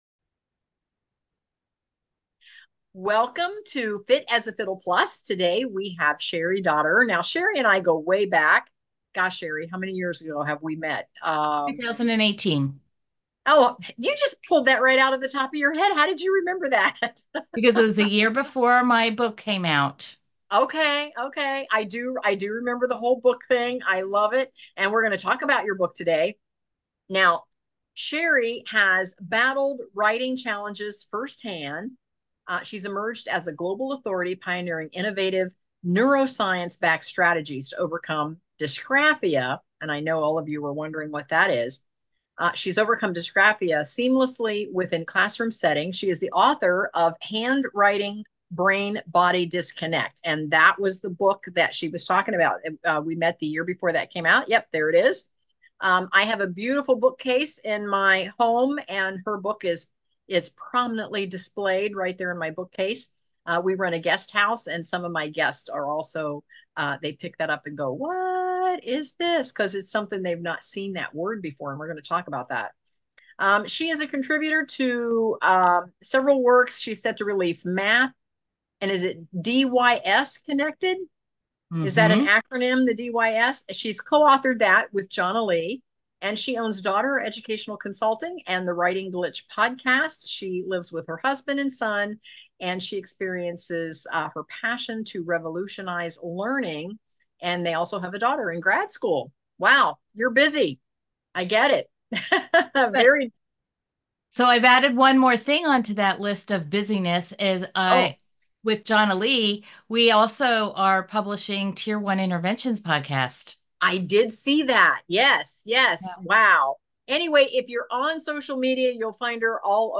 Health and Wellness | Fit as a Fiddle Plus | Interview